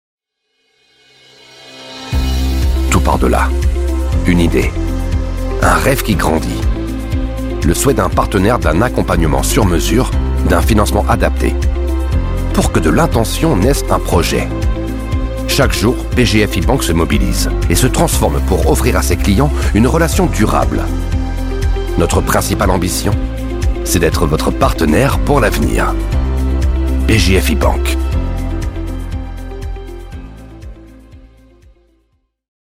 Voz en off en Francés
Corporativo